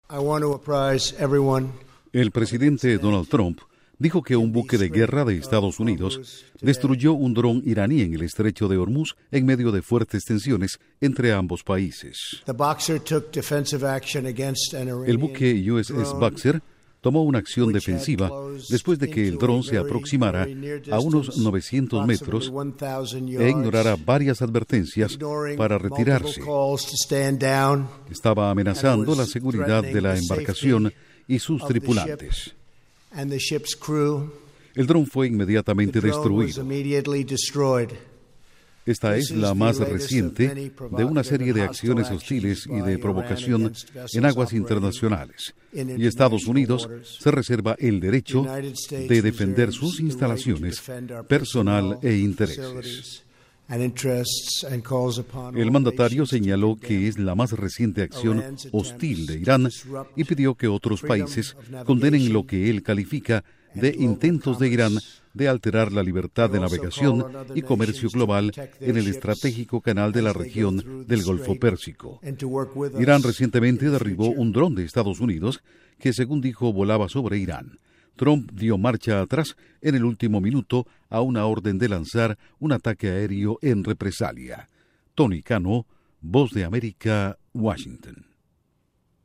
Duración: 1:29 Con declaraciones de Trump